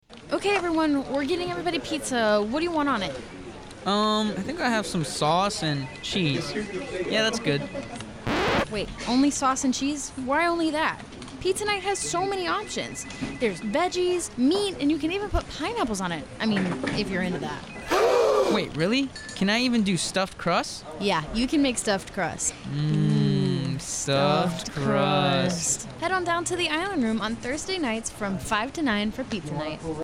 Pizza NightNSFDiegoGarciaRadioSpot